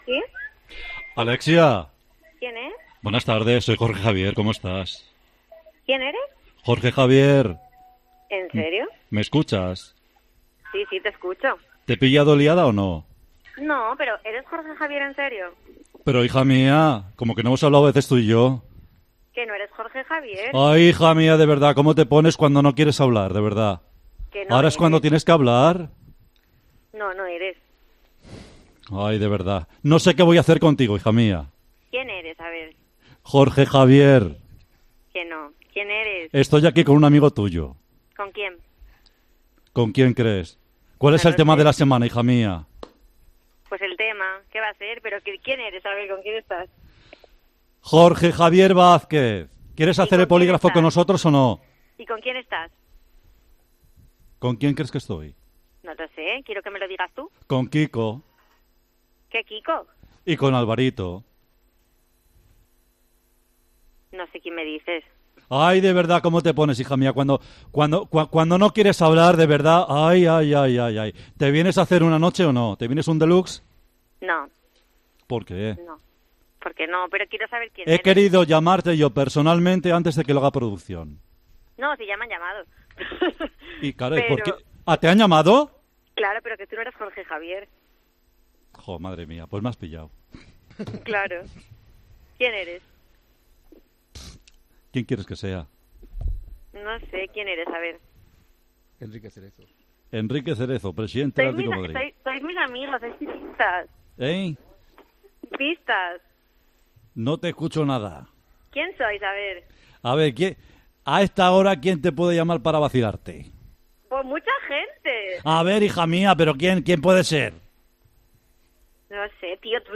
El 'Grupo Risa' ha llamado a Alexia Rivas en directo en Tiempo de Juego durante 'La Primera Hora'.
Con Paco González, Manolo Lama y Juanma Castaño